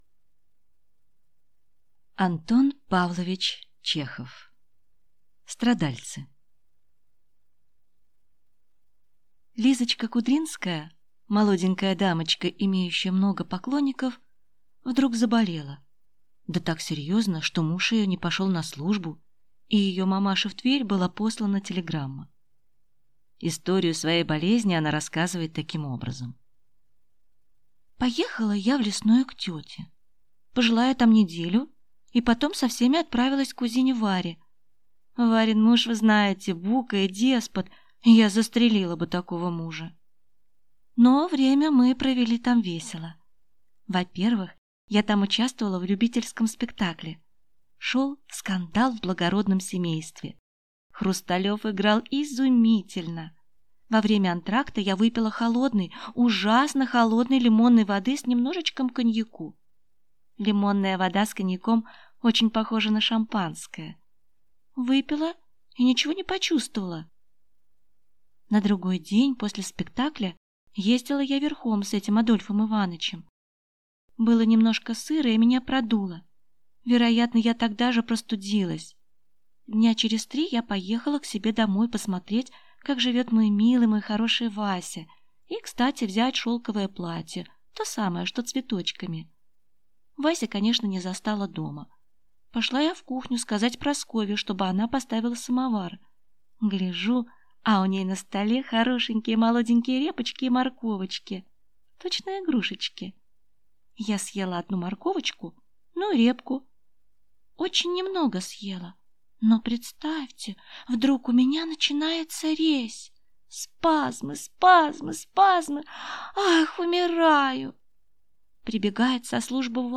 Аудиокнига Страдальцы | Библиотека аудиокниг
Прослушать и бесплатно скачать фрагмент аудиокниги